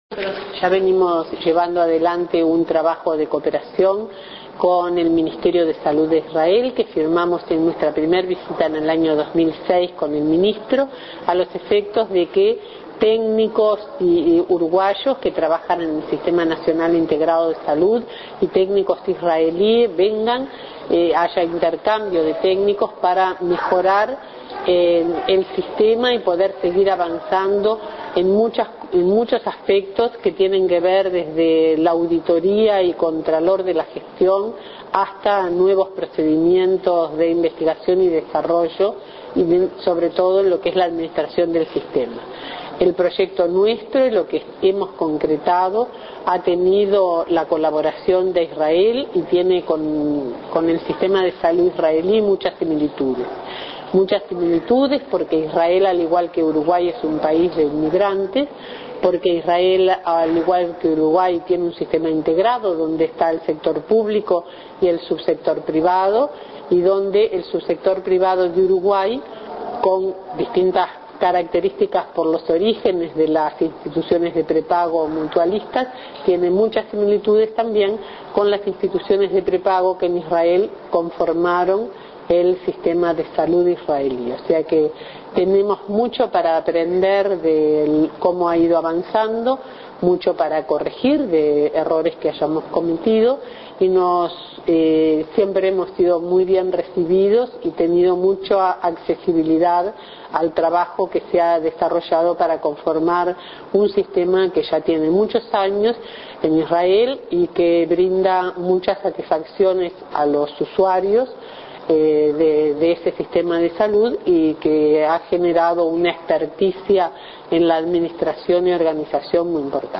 Declaraciones a la prensa de la Ministra María Julia Muñoz, tras la presentación del "Plan de Cooperación en los campos de Salud y Medicina 2009-2014".